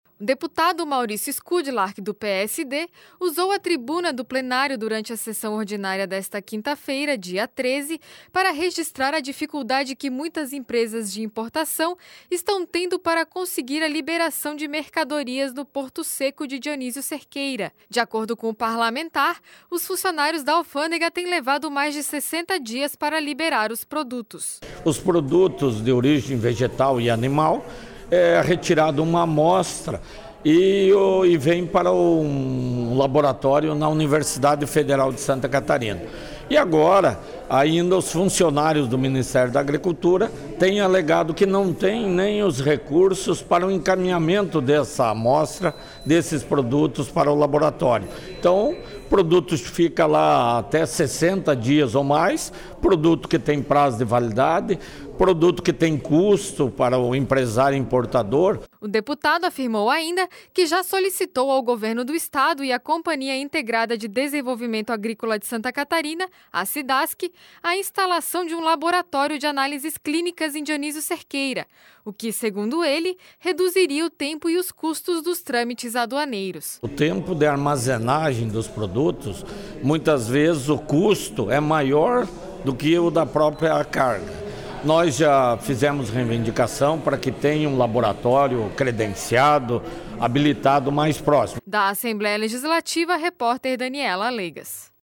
Entrevista: Deputado Maurício Eskudlark (PSD)